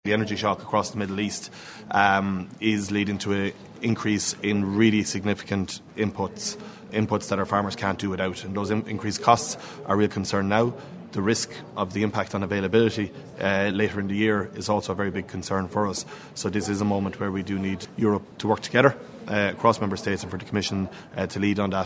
Speaking ahead of a meeting of the Agrifish Council in Luxembourg, Minister Heydon says the crisis in the Middle East has highlighted Europe’s vulnerability to changes in the availability and price of fuel and fertiliser: